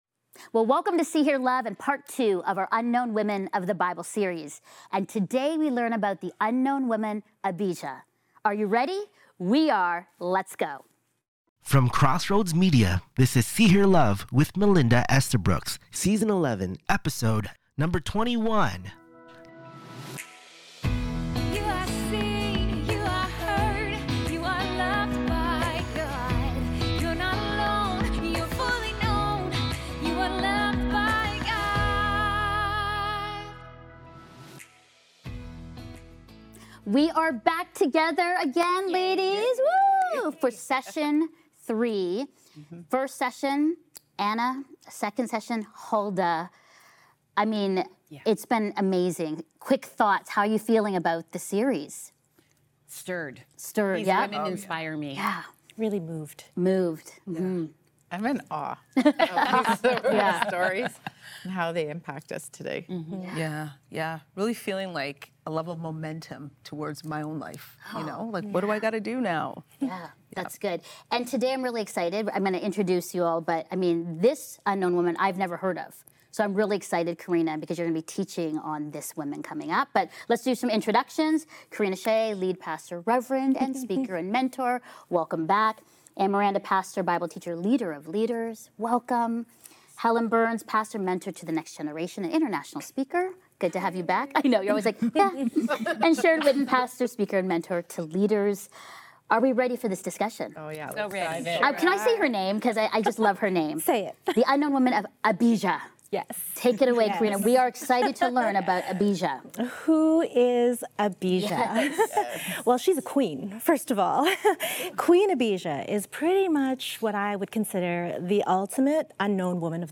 This honest and hope-filled discussion reminds every woman that even unseen faithfulness can spark generational transformation.